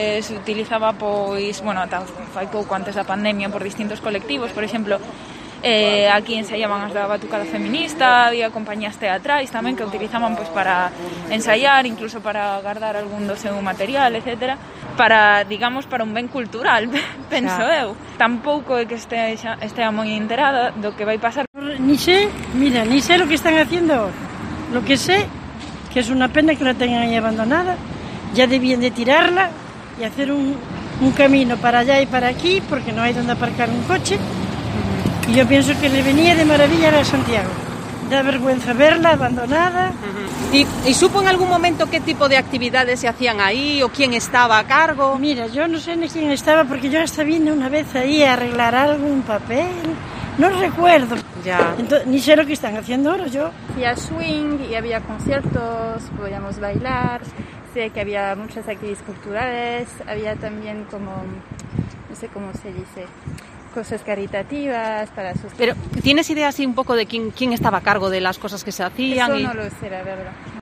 Vecinos y comerciantes nos cuentan lo que saben de las actividades que aquí se organizan